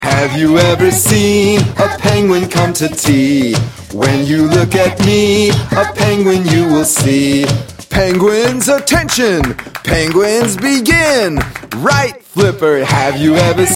Action Song Lyrics and Sound Clip